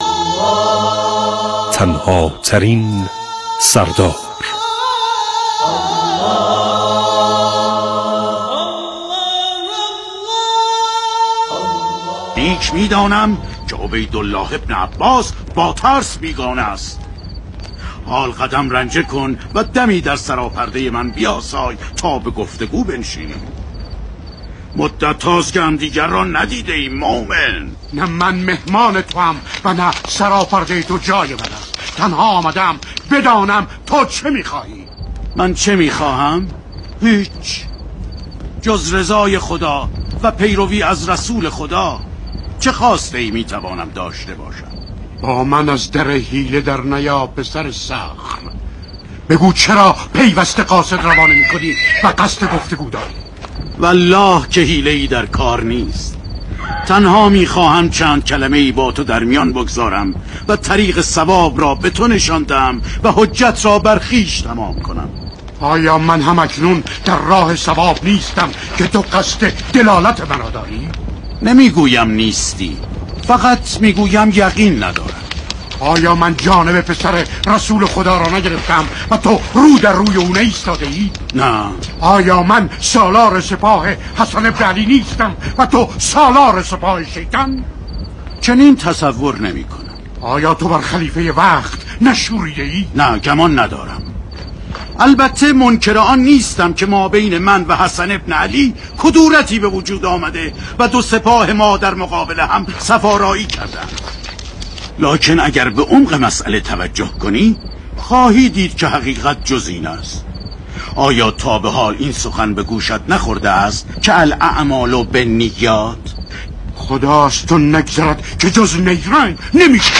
این ویژه‌برنامه برگرفته از بخش‌هایی از سریال تلویزیونی «تنهاترین سردار» است که از 9 تا 13 مهرماه به صورت نمایشی ساعت 9:50 به روی آنتن می‌رود.